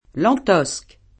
vai all'elenco alfabetico delle voci ingrandisci il carattere 100% rimpicciolisci il carattere stampa invia tramite posta elettronica codividi su Facebook Lantosque [fr. l 3 t 0S k ] top. (Nizz.) — it. Lantosca [ lant 0S ka ]